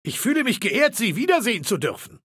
Datei:Maleold01 ms06fin greeting 000284b2.ogg
Fallout 3: Audiodialoge